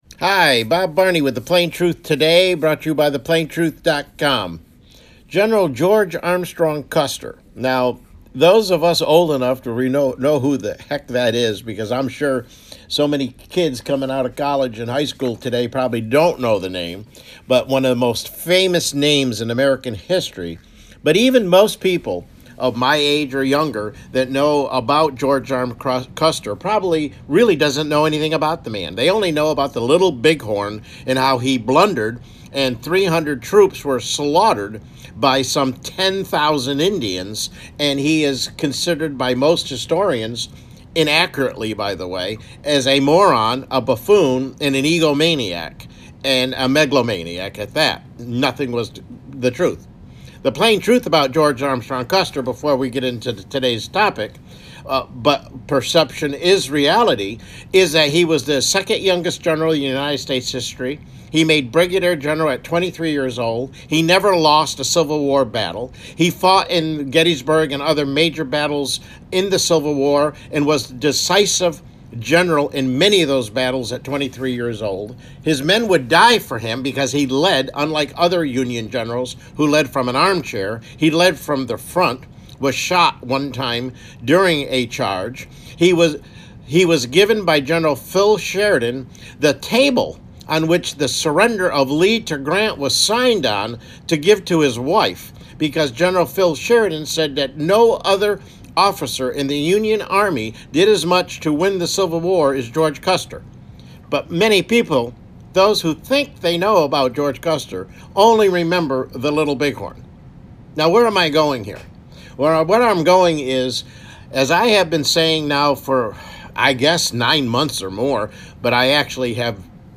CLICK HERE TO LISTEN TO THE PLAIN TRUTH TODAY MIDDAY BROADCAST: DHS, ICE, Raids, and the American Public